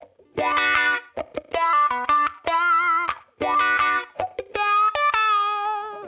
Index of /musicradar/sampled-funk-soul-samples/79bpm/Guitar
SSF_StratGuitarProc2_79B.wav